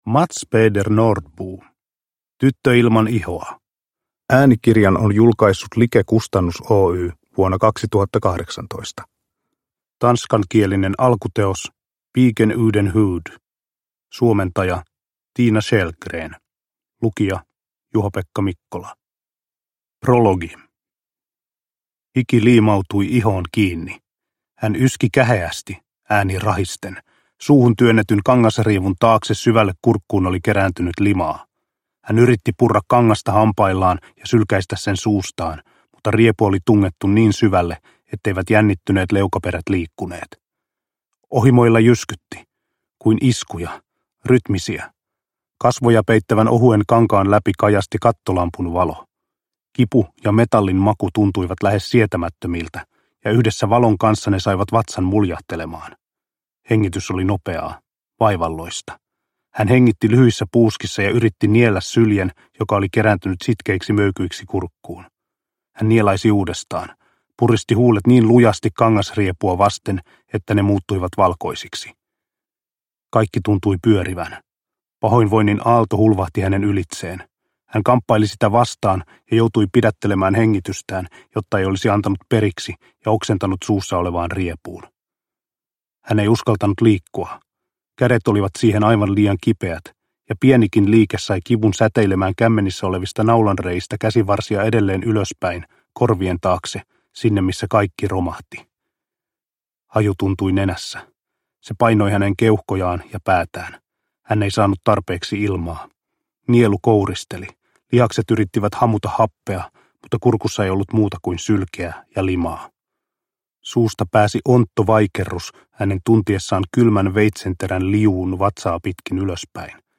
Tyttö ilman ihoa – Ljudbok – Laddas ner